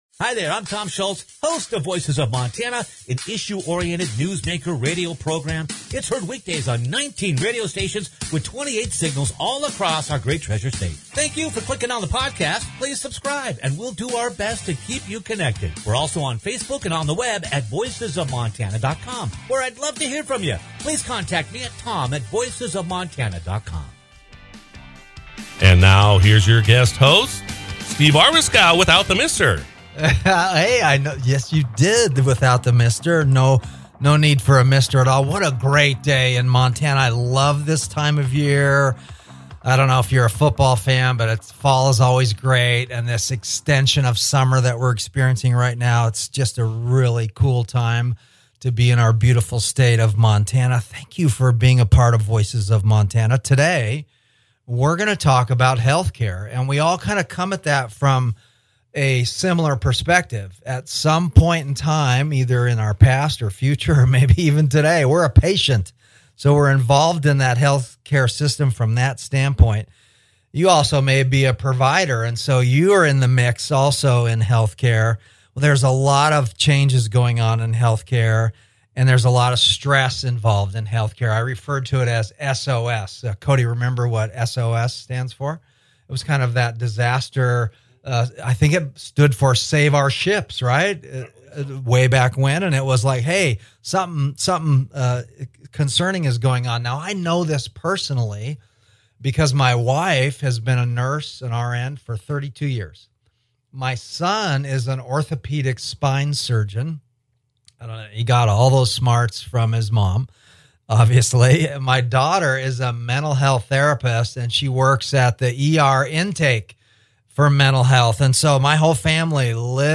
A Candid “Healthcare in Montana” Conversation with MHA President Ed Buttrey - Voices of Montana